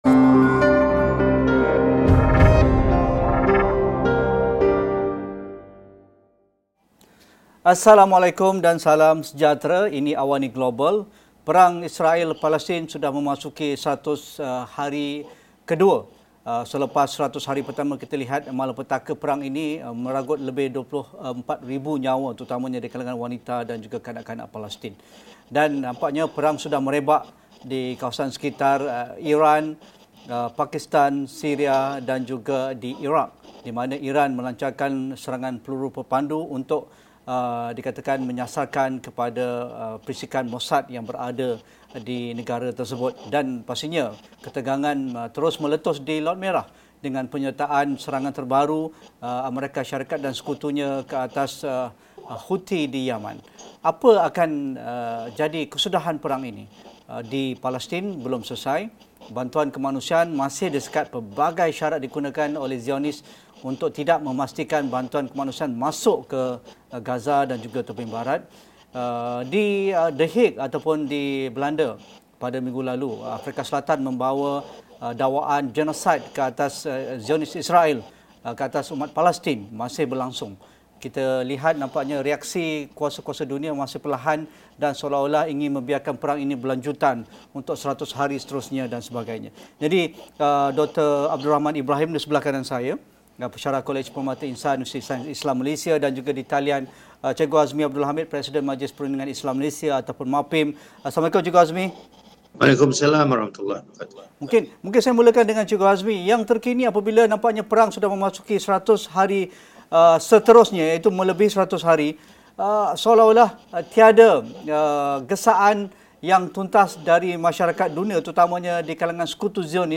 Apakah kesudahan konflik yang mengorbankan lebih 24,000 nyawa rakyat Palestin dan 60% kemudahan awam di Gaza? Diskusi dan analisis perang Israel – Palestin yang sudah memasuki 100 hari seterusnya dalam AWANI Global malam ini.